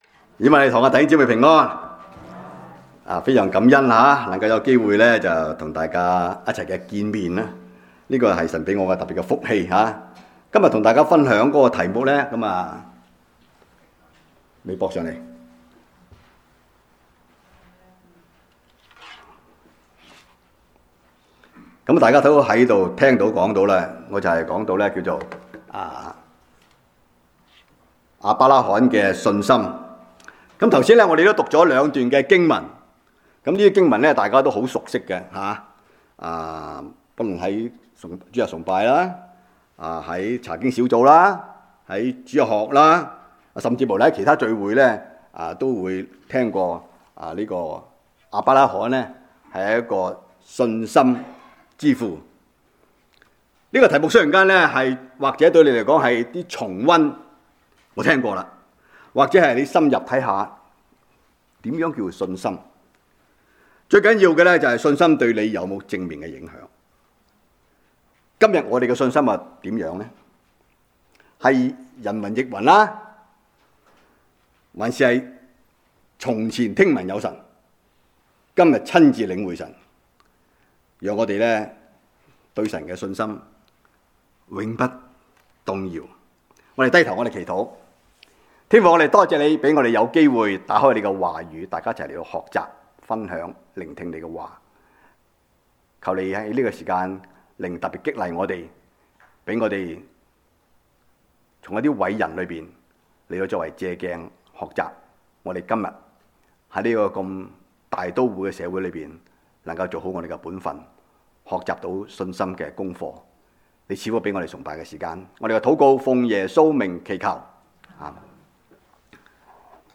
講道錄音